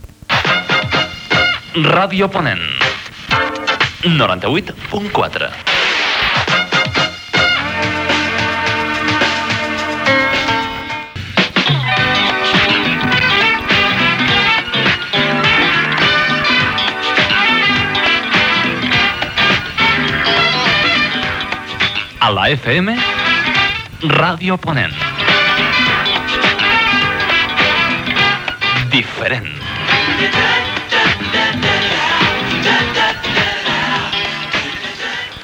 Indicatius de l'emissora